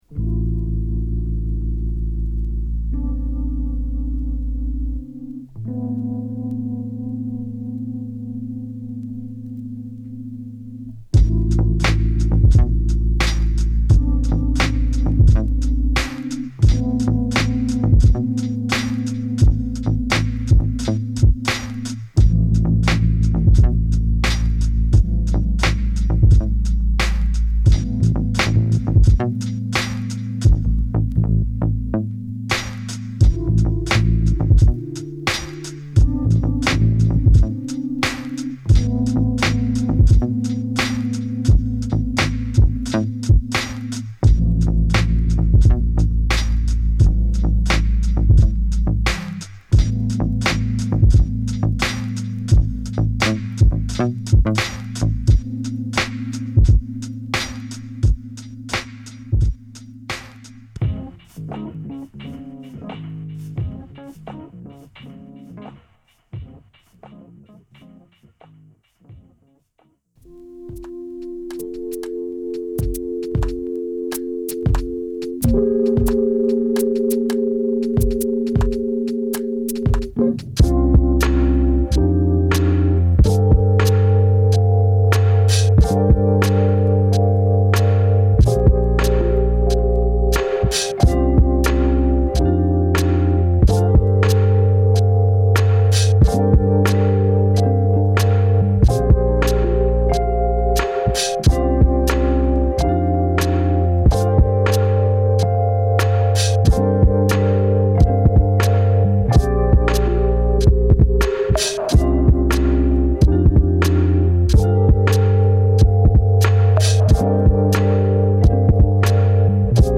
リラックスや有機的な生命感を見事に演出している。